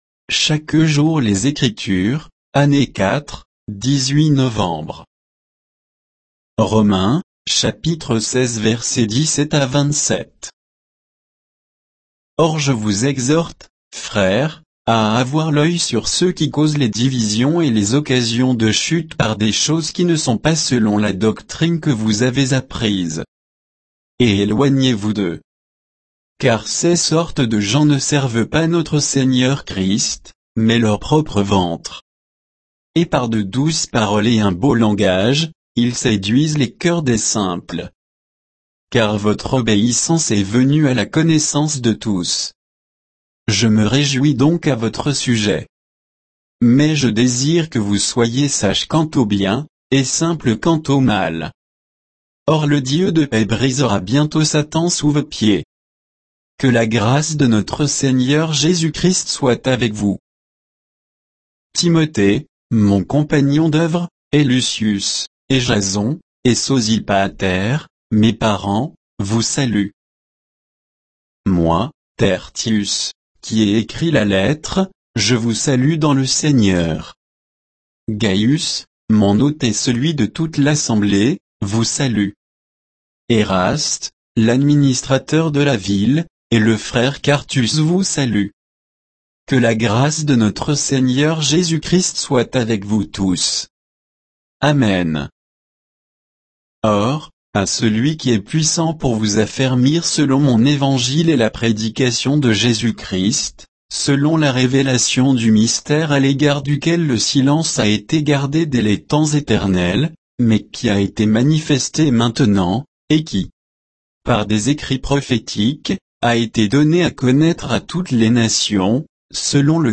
Méditation quoditienne de Chaque jour les Écritures sur Romains 16, 17 à 27